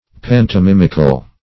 Search Result for " pantomimical" : The Collaborative International Dictionary of English v.0.48: Pantomimic \Pan`to*mim"ic\, Pantomimical \Pan`to*mim"ic*al\, a. [Cf. F. pantomimique.]